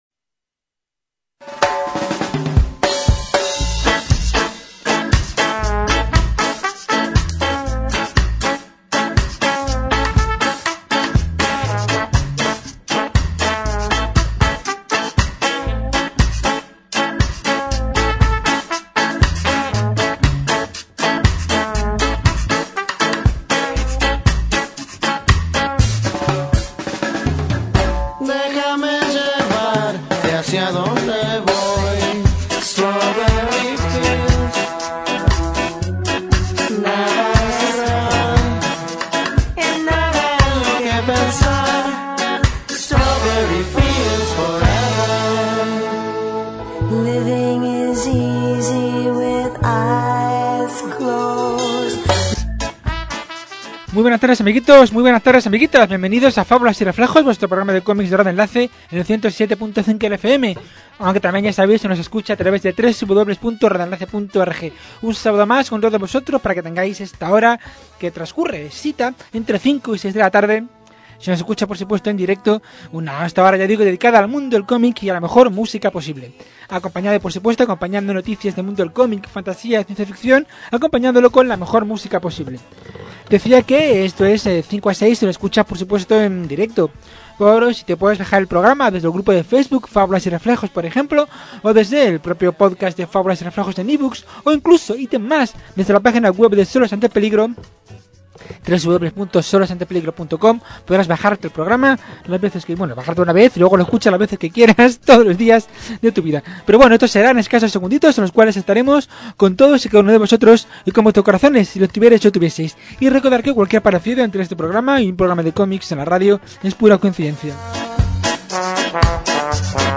Es por ello que encontrarás un programa repleto de grandes colecciones con clásicos de toda la vida y como no, todo ello siempre acompañado de la mejor música posible... o la que le salga a él del felisín .